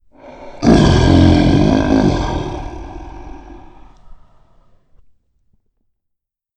paoxiao.mp3